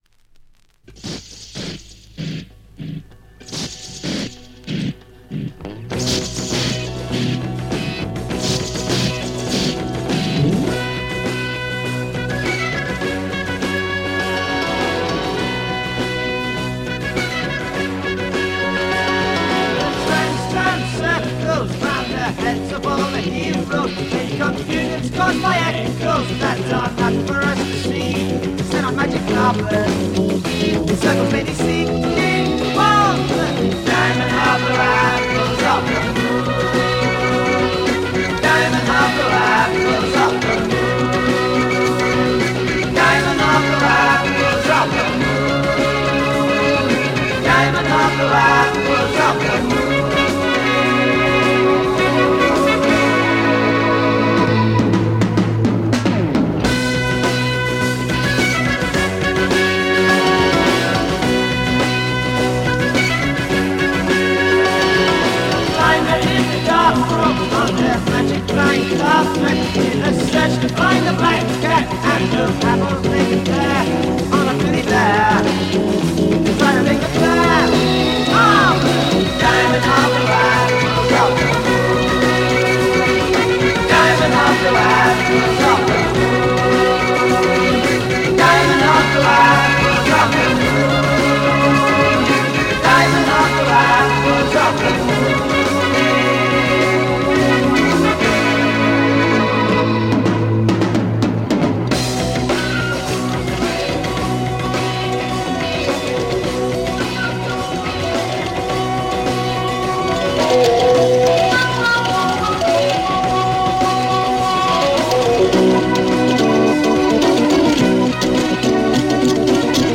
Classic UK Psychedelic LSD French Pic NM!